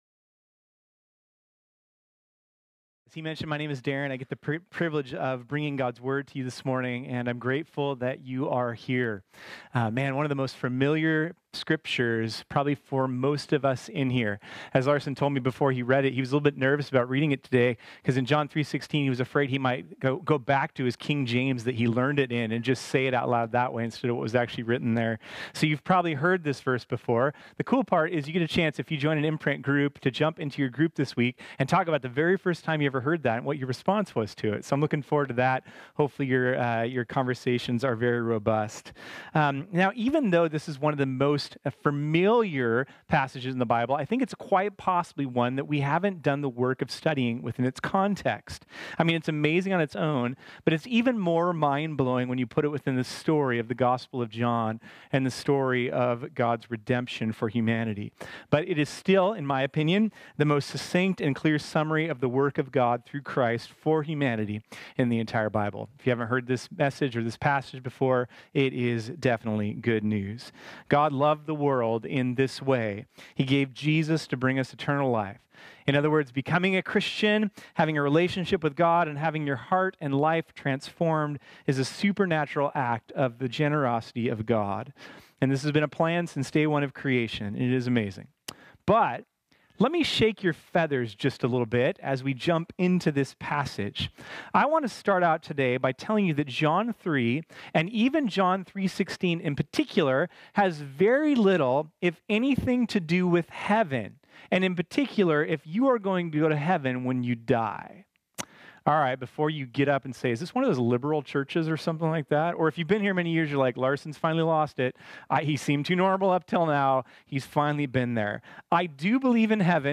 This sermon was originally preached on Sunday, October 13, 2019.